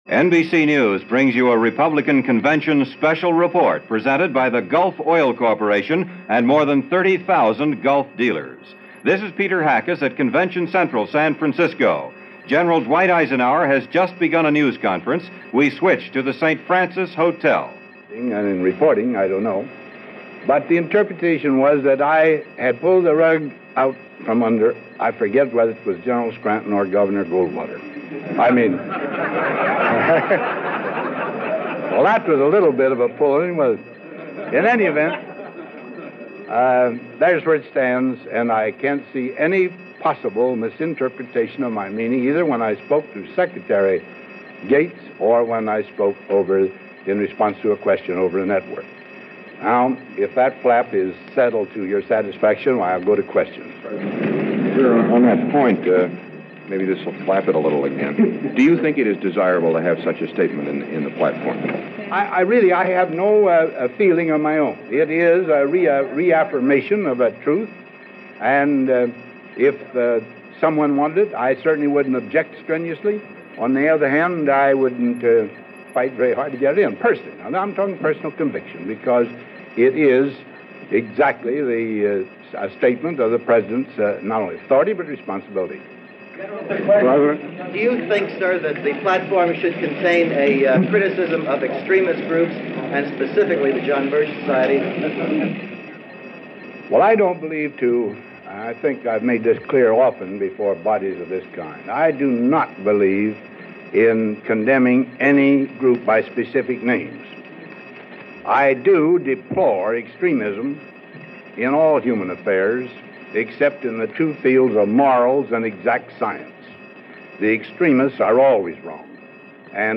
1964 Convention Preview – Eisenhower Press Conference – NBC Radio
But like everything else at this convention, it was contentious and relentless and Eisenhower was adamant in his neutrality. As a taste of what is to come, starting next week, here is that Press Conference with former President Eisenhower with commentary, and a swift cut-back to New York for comments by NBC Radio reporters – all on July 13, 1964.